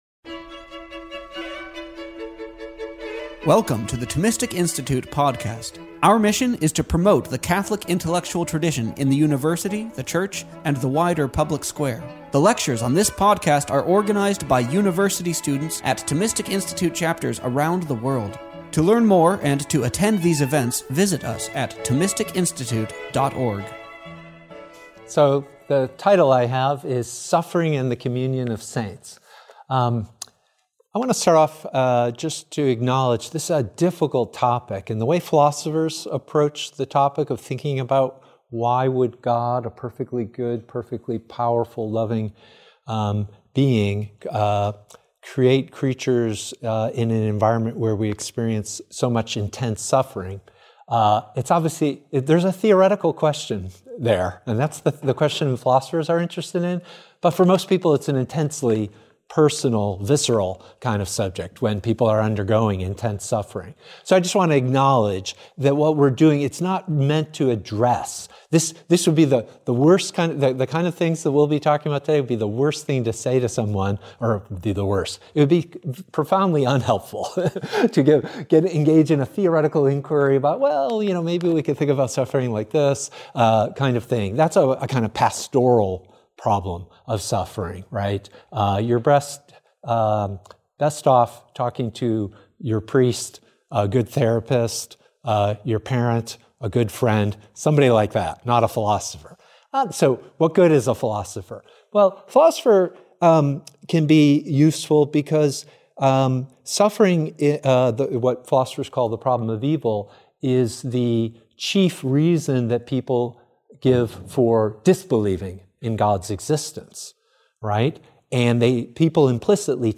This lecture was given on December 3rd, 2025, at University of Scranton.